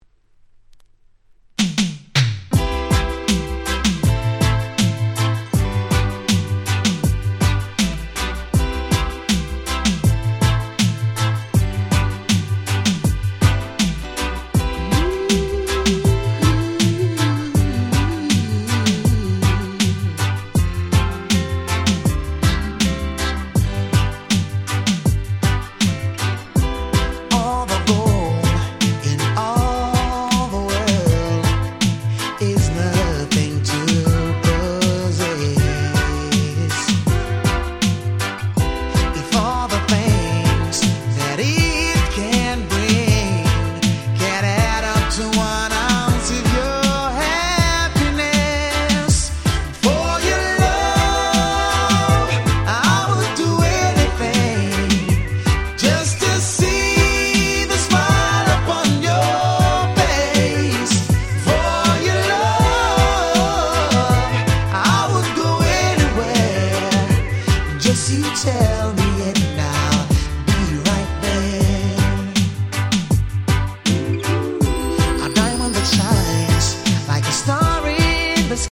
全て有名曲のLovers Reggaeカバー！